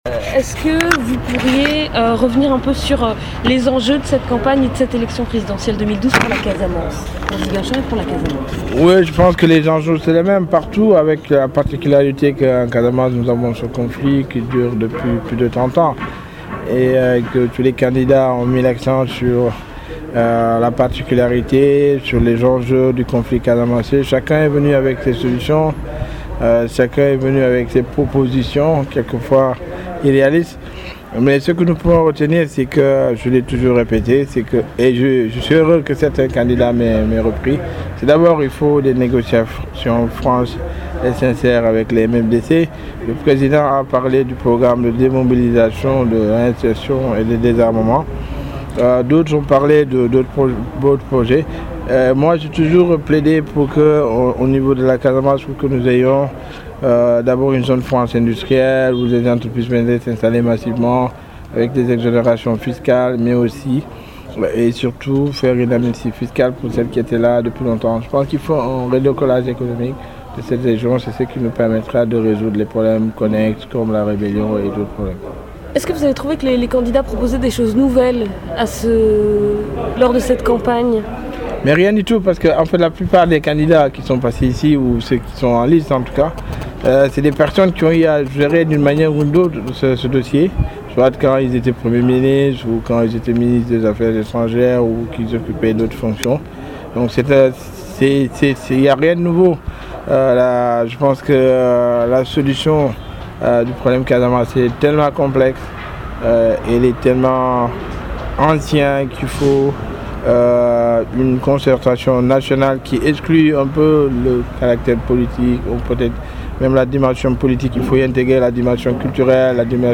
Ecoutez l'entretien d'Abdoulaye Baldé avec la BBC